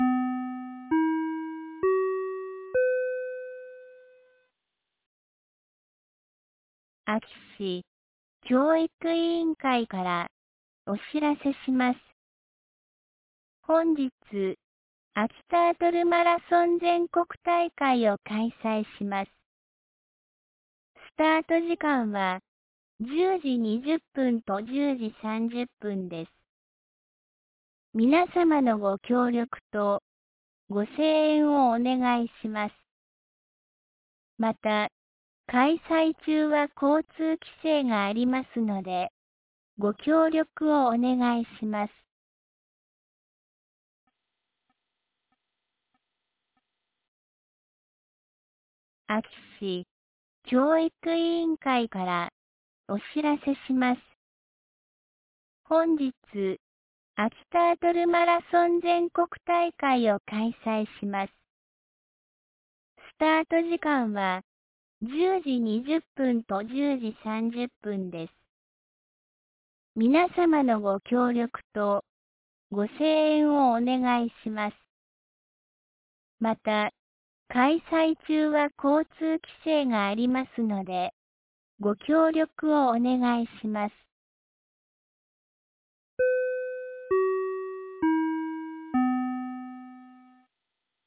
2024年12月08日 08時31分に、安芸市より全地区へ放送がありました。